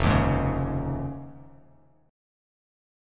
Fm7#5 chord